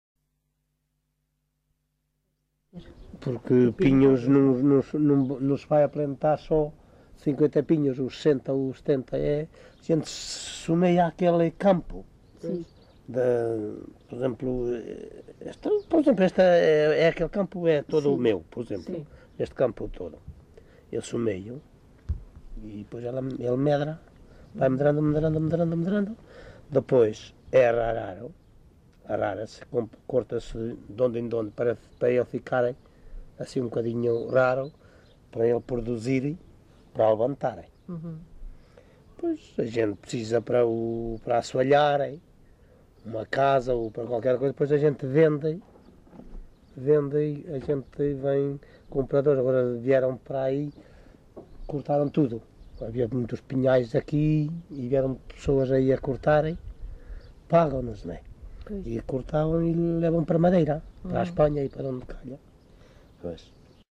LocalidadeOuteiro (Bragança, Bragança)